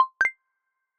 Notifications 🔔